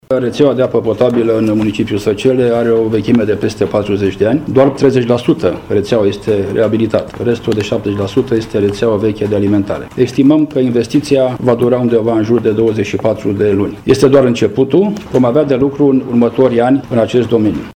Primarul municipiului Săcele, Virgil Popa, a anunțat într-o conferință de presă că rețelele de apă potabilă, din zona Turcheș – Baciu, vor intra într-o etapă obligatorie de reabilitate, având în vederea vechimea instalațiilor: